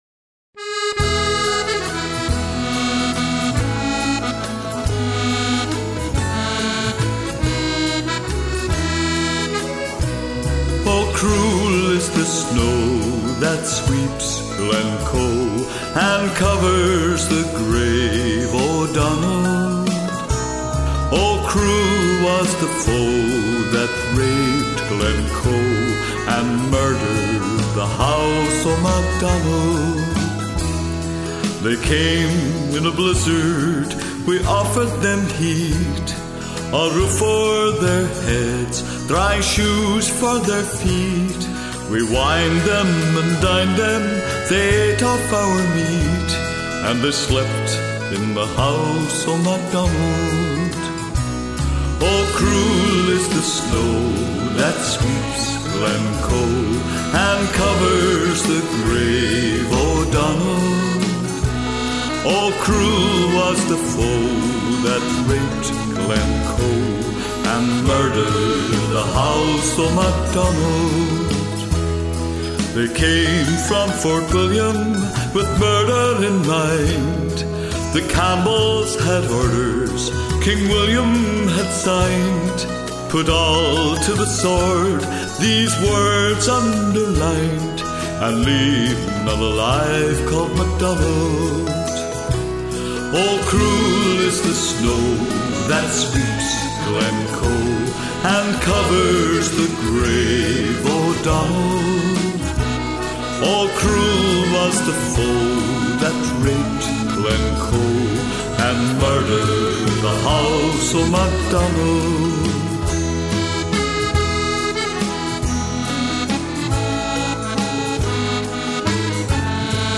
Genre: Disco.